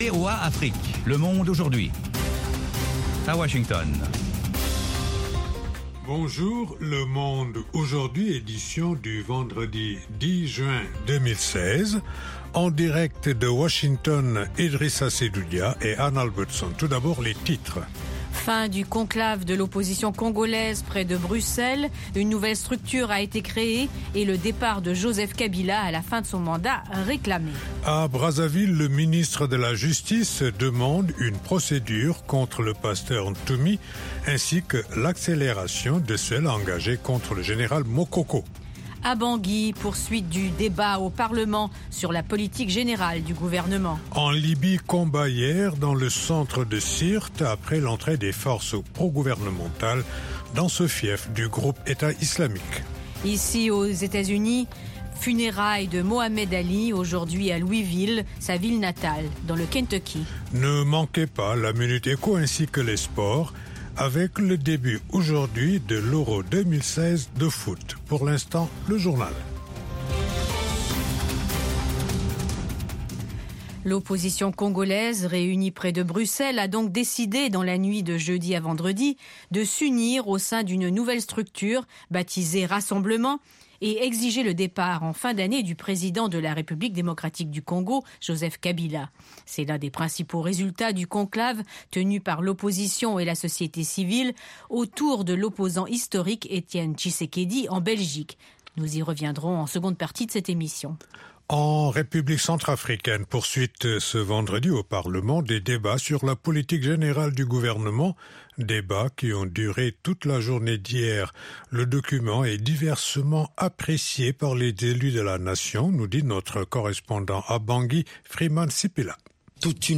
Le Monde aujourd'hui, édition pour l'Afrique de l'Ouest, une information de proximité pour mieux aborder les préoccupations de nos auditeurs en Afrique de l’Ouest. Toute l’actualité sous-régionale sous la forme de reportages et d’interviews.
Le Monde aujourd'hui, édition pour l'Afrique de l’Ouest, c'est aussi la parole aux auditeurs pour commenter à chaud les sujets qui leur tiennent à coeur.